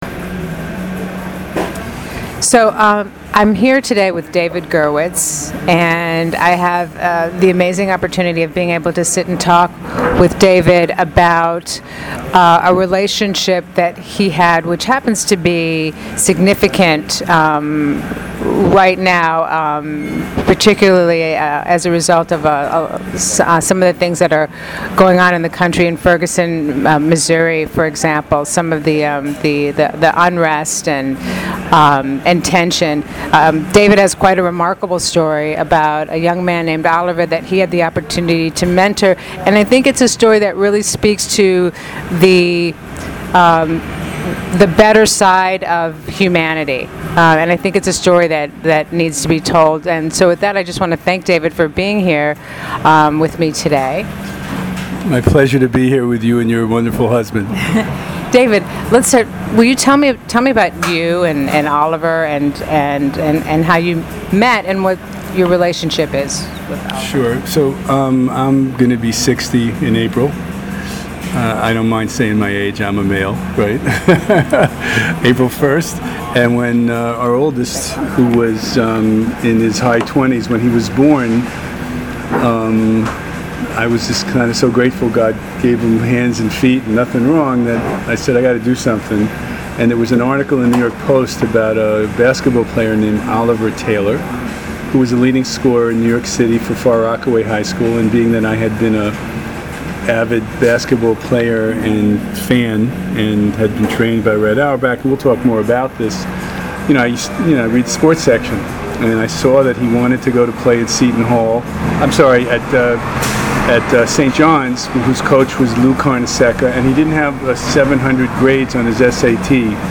Listen to the audio version of this interview